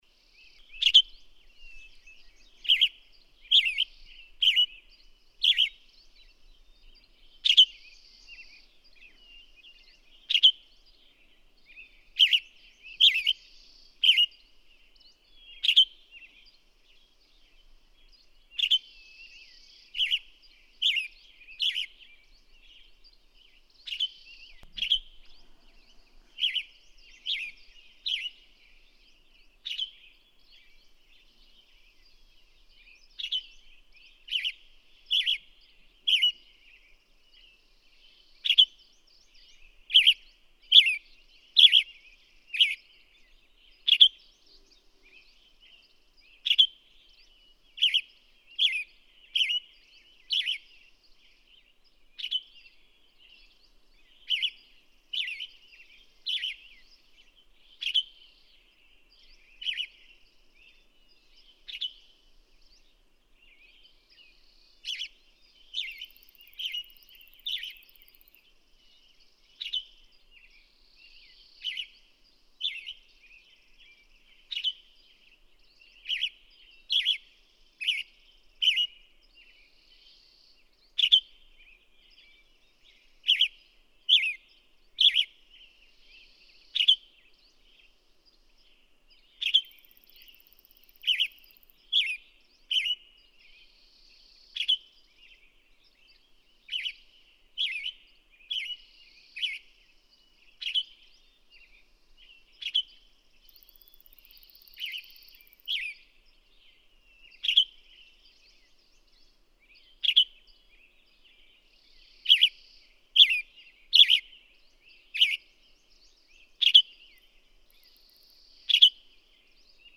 WESTERN TANAGER
♫602. Western tanager: Slowed dawn singing, but continuous, each song phrase now standing alone, with the pit-er-ick call interspersed. Red-breasted nuthatch at 5:50. June 13, 2009. Malheur National Wildlife Refuge, Burns, Oregon. (6:13)
602_Western_Tanager.mp3